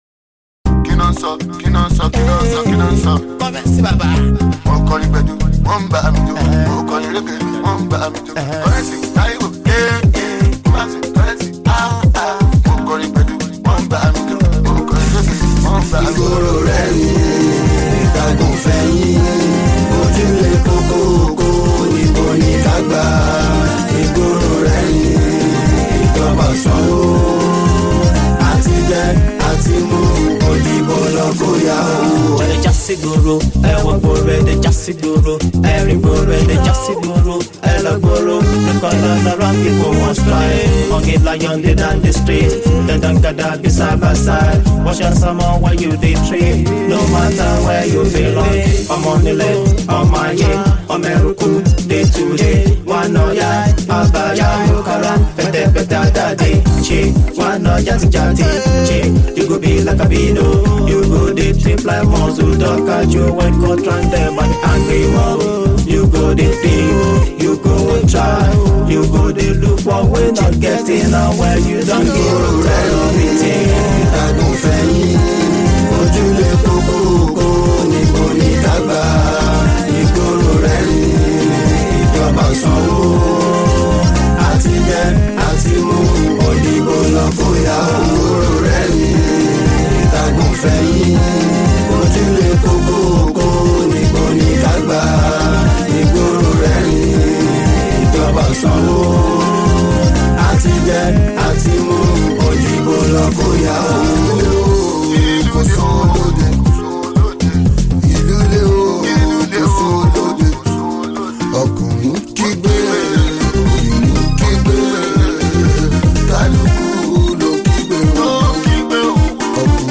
Fuji
Super talented Yoruba Fuji singer